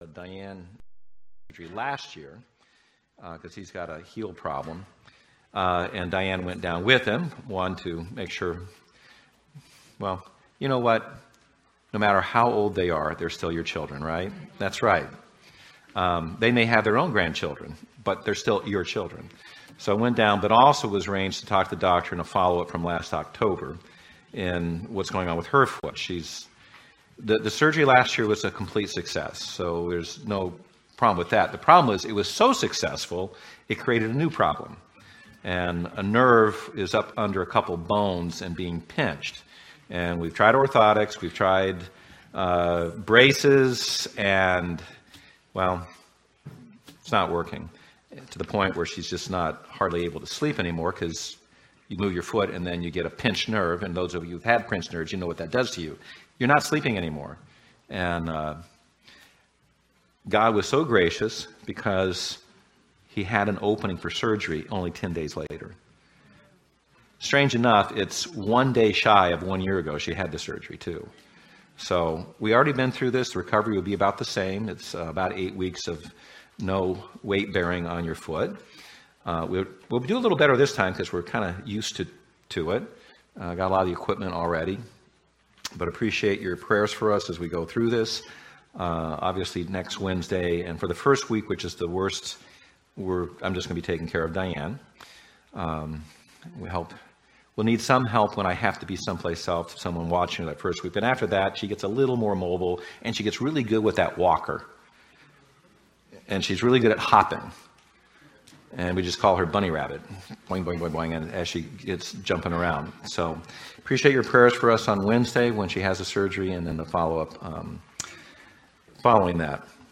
Sermons 2020